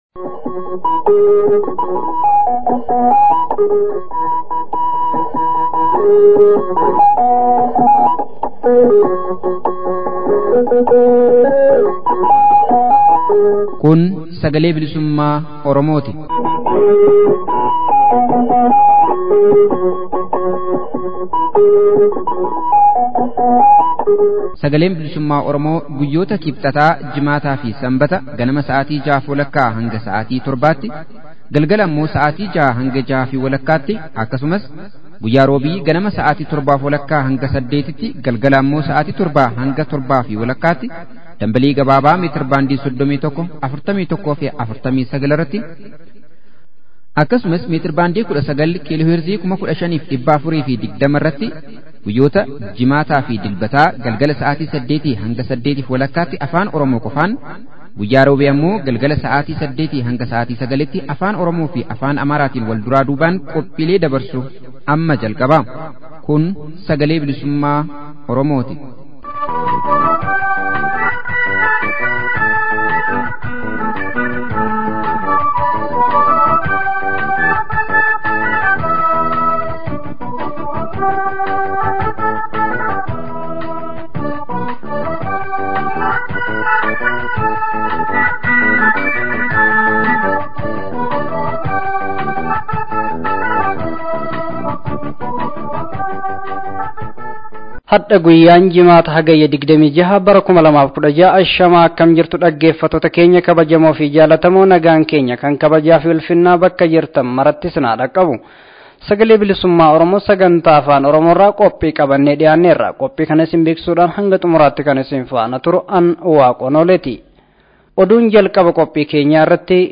Oduu, Gabaasa Tarkaanfii Gootummaa Atleet Fayyisaa Leellisaa fudhate irratti fuulleffatee fi Hiriira mormii Hawaasa Oromoo Kanaadaa -Toroontoo.